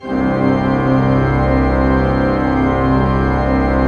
Index of /90_sSampleCDs/Propeller Island - Cathedral Organ/Partition I/PED.V.WERK R